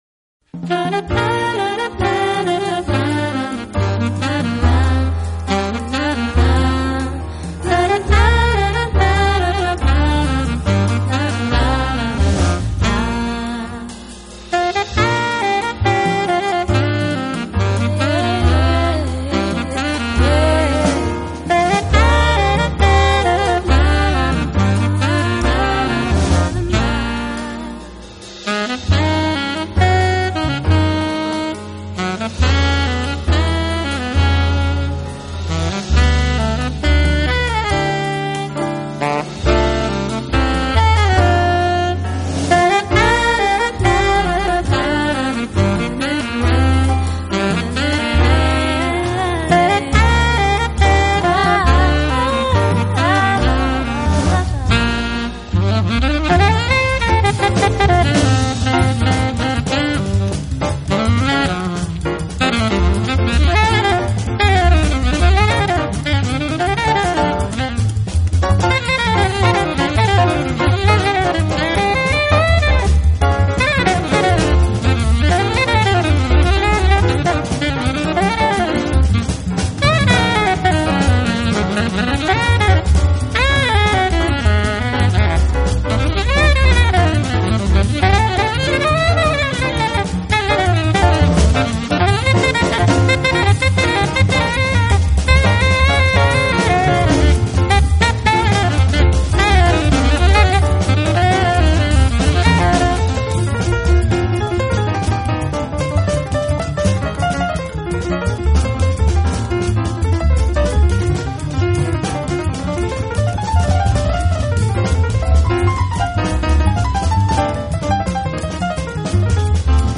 Category Jazz Instrument CDs, Saxophone, Alto Sax, Jazz
Studio/Live  Studio
Mono/Stereo  Stereo